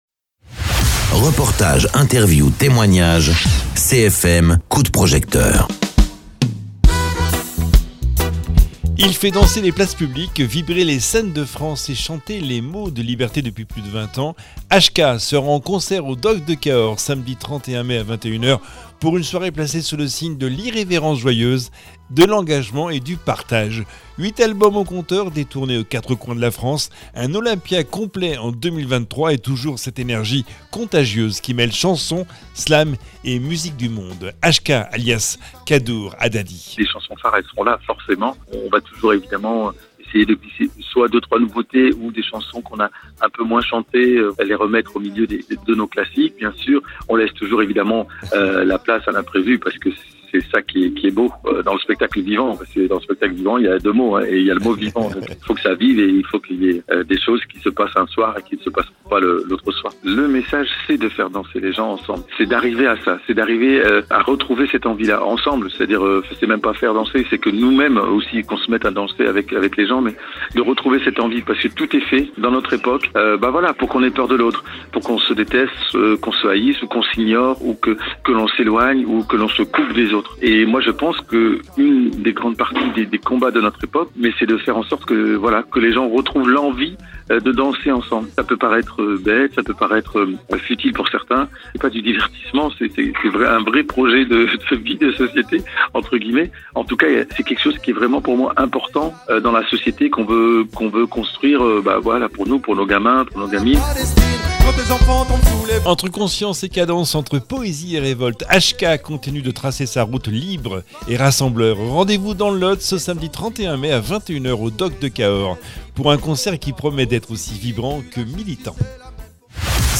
Interviews
Invité(s) : HK, de son vrai nom Kaddour Hadadi, auteur-compositeur-interprète.